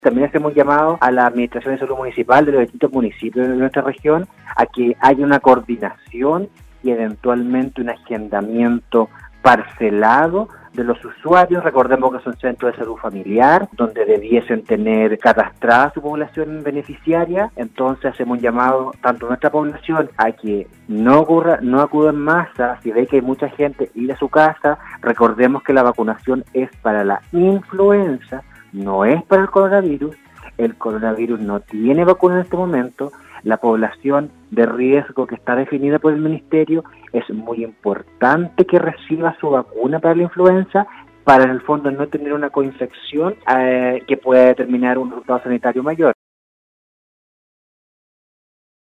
ENTREVISTA-1.mp3